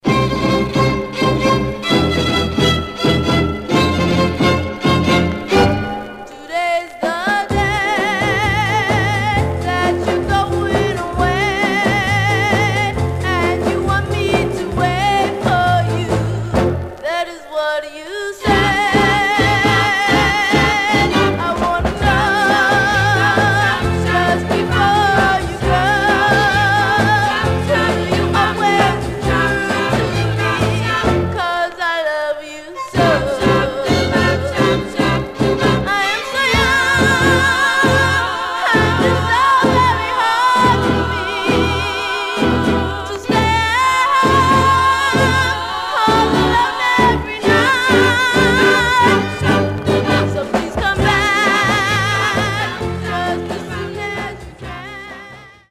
Some surface noise/wear Stereo/mono Mono
White Teen Girl Groups